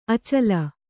♪ acala